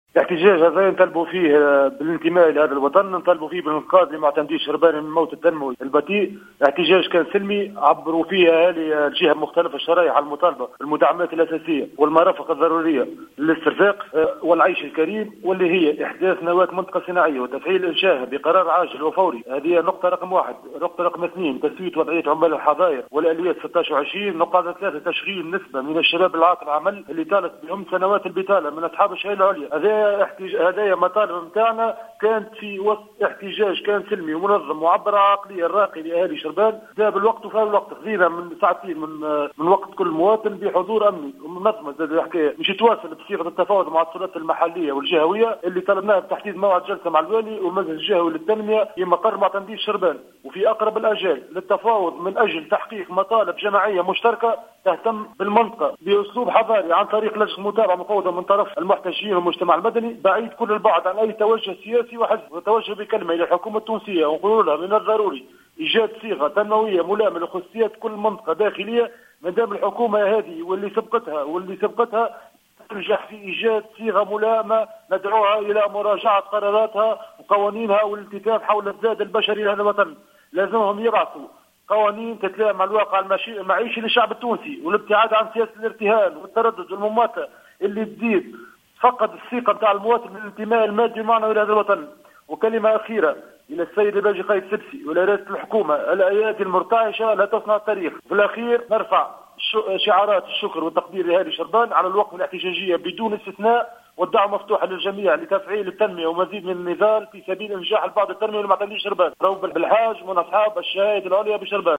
أحد المحتجين يتحدث للجوهرة أف أم